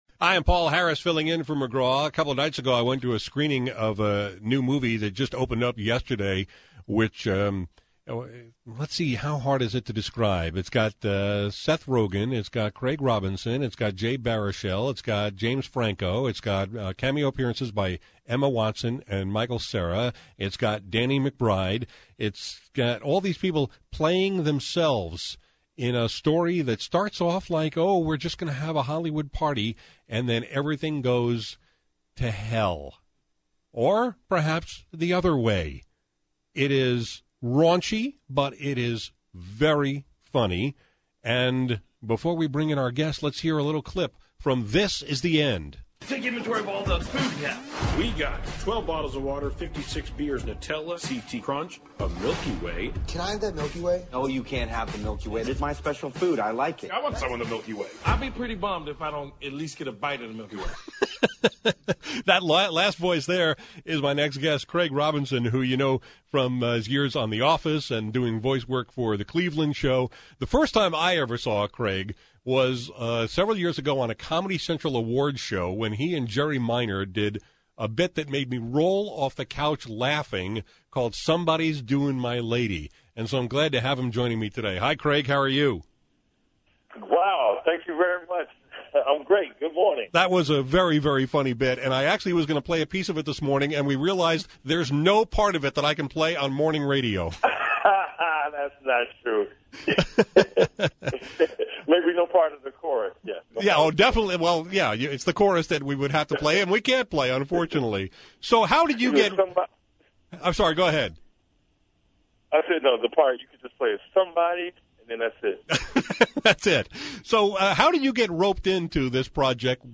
Here’s my conversation with Craig Robinson, one of the stars of “This Is The End” (along with Seth Rogen, James Franco, Jonah Hill, Danny McBride, Michael Cera, Emma Watson and others — all playing versions of themselves).